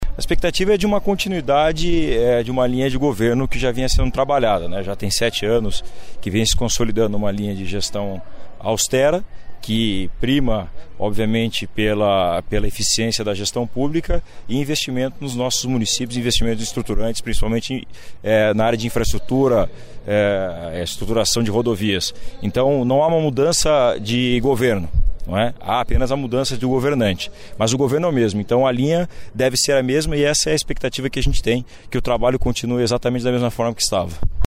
Ouça entrevista com o parlamentar.(Sonora)